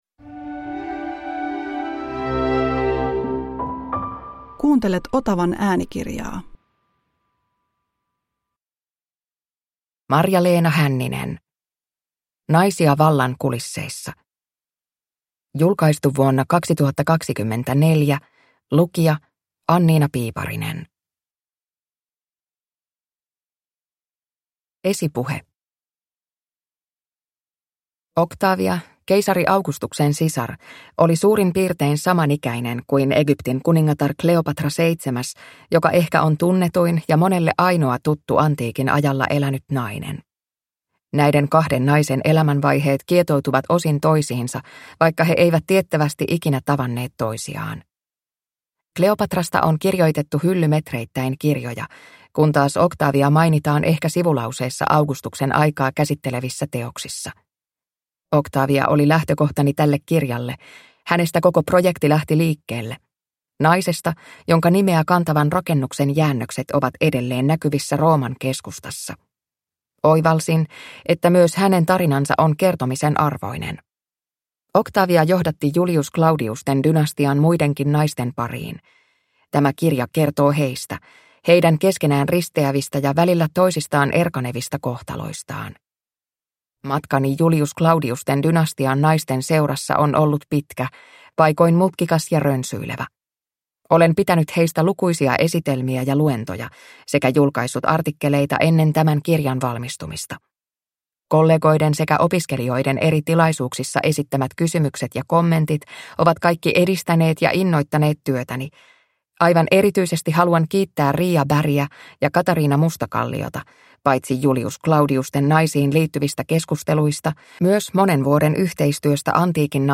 Naisia vallan kulisseissa – Ljudbok